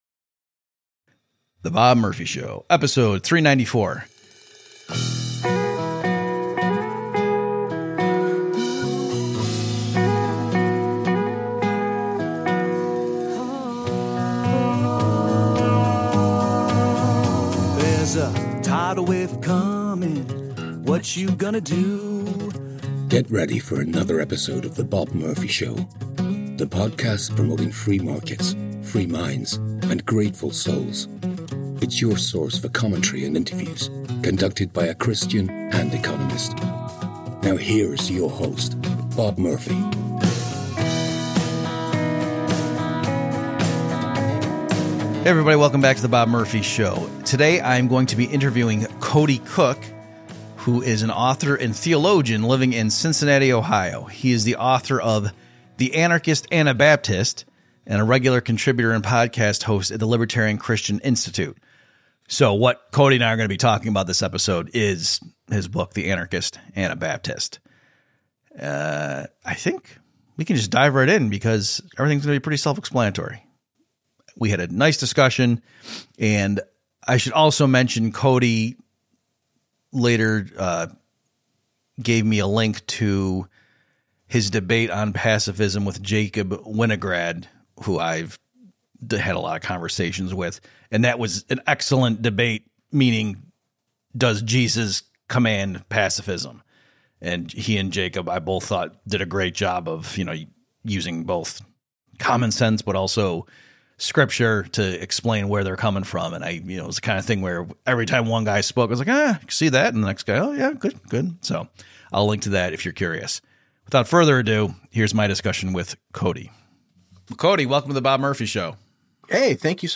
I had the privilege of being interviewed